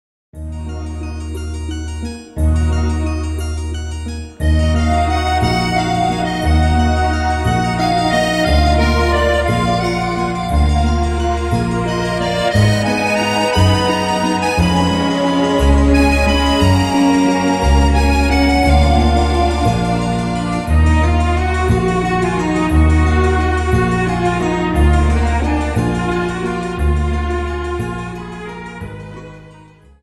Dance: Viennese Waltz 59